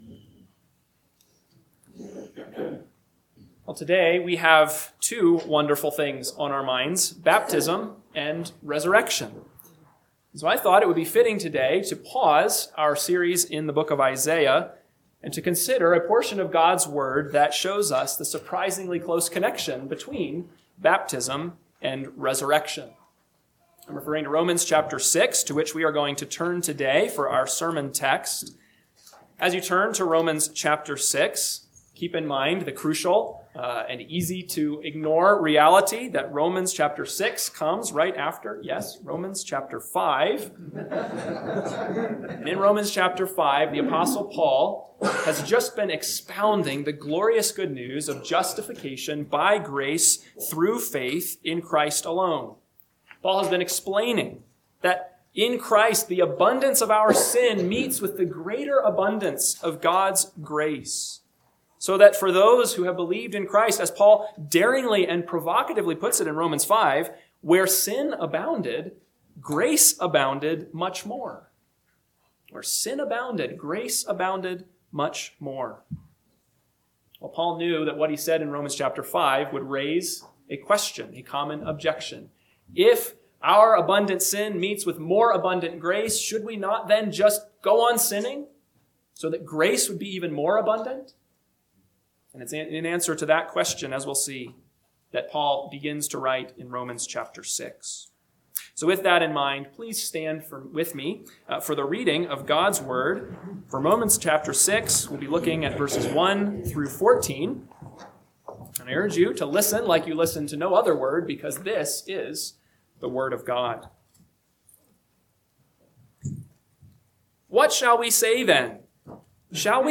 AM Sermon – 4/5/2026 – Romans 6:1-14 – Northwoods Sermons